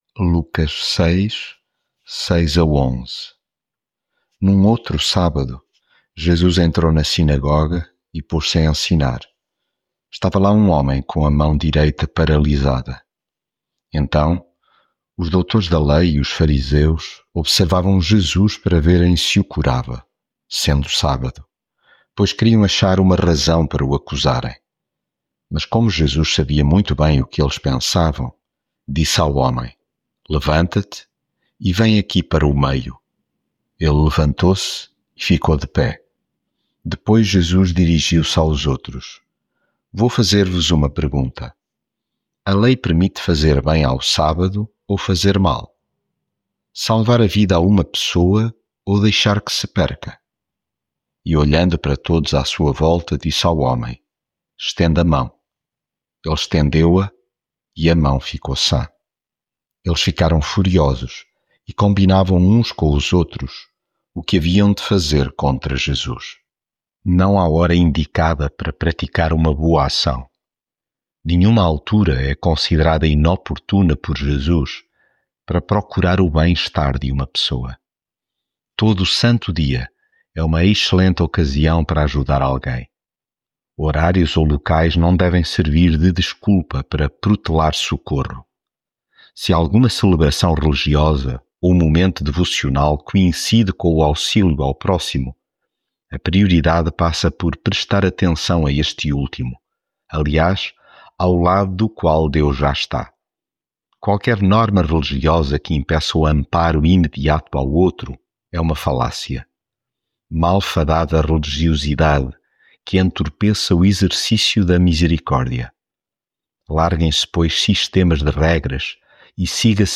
devocional Lucas leitura bíblica Num outro sábado, Jesus entrou na sinagoga e pôs-se a ensinar.